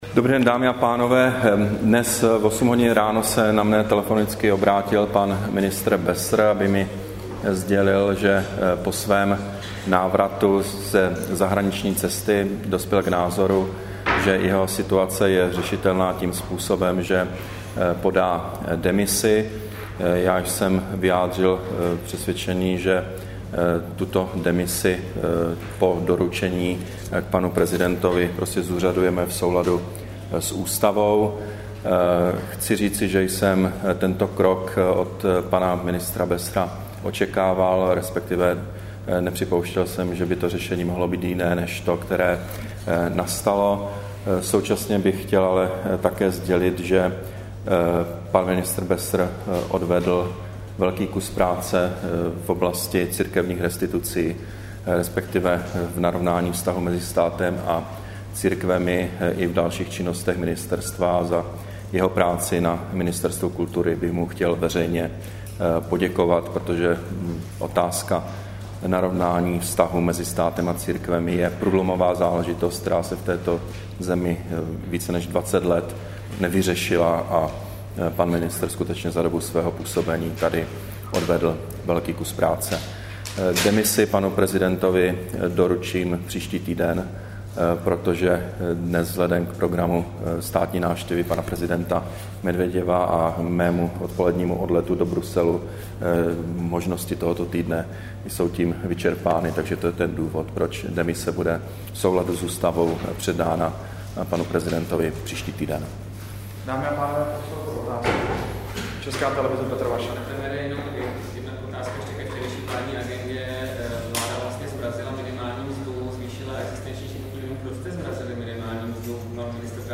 Brífink premiéra Petra Nečase k rezignaci ministra kultury Jiřího Bessera a k Evropské radě, 8. prosince 2011